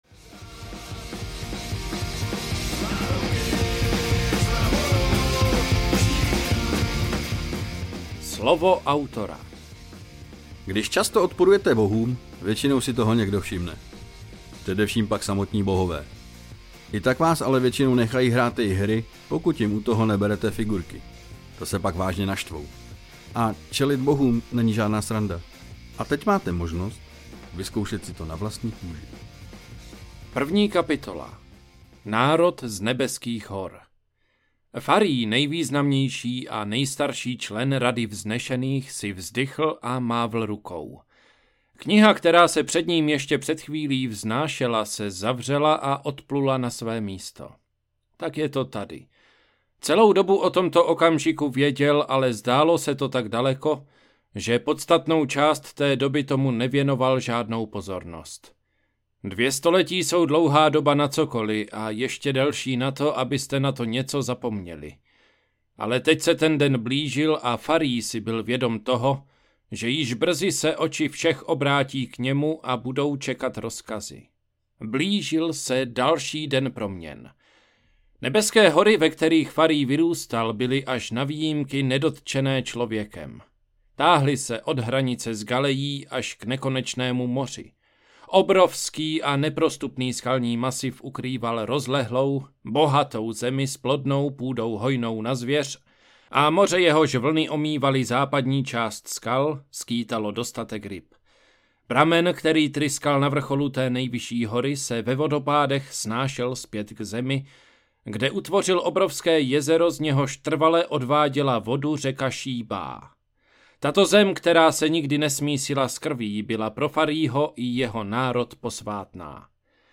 Tantrická síla audiokniha
Ukázka z knihy
tantricka-sila-audiokniha